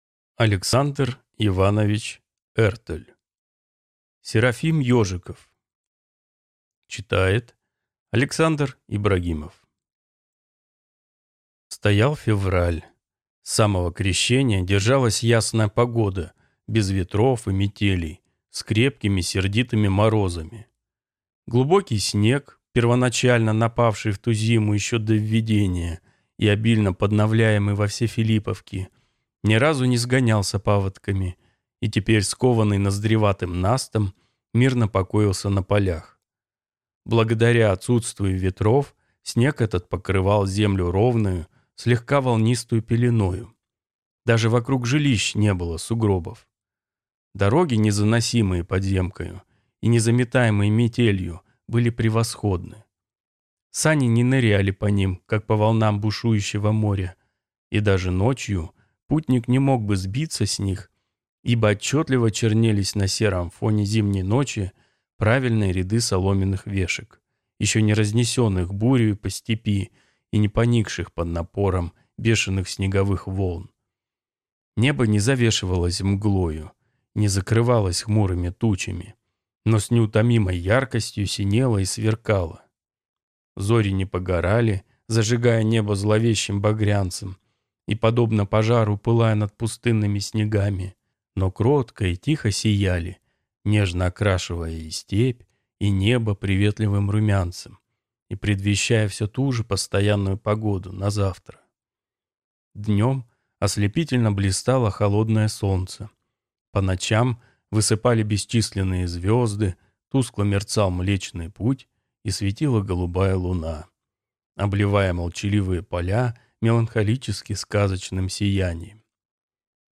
Аудиокнига Серафим Ежиков | Библиотека аудиокниг